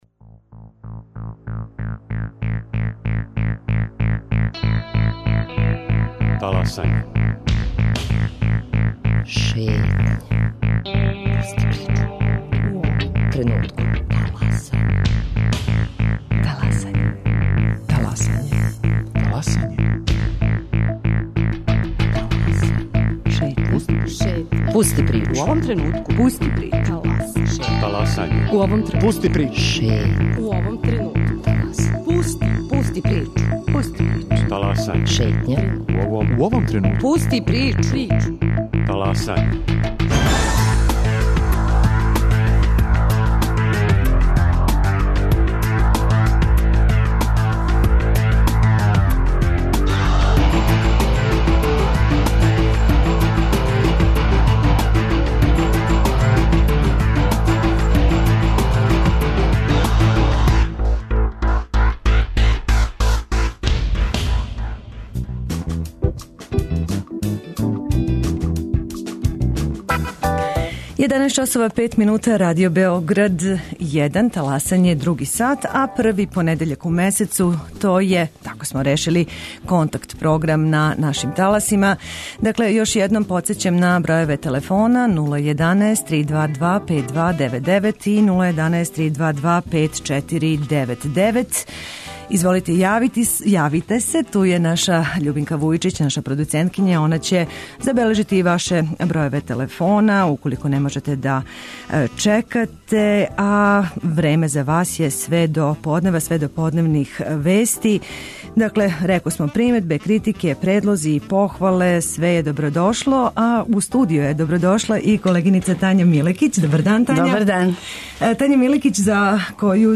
С обзиром да је први понедељак у месецу, други сат нашег преподневног програма резервисан је за слушаоце Радио Београда 1 и њихове оцене нашег програма.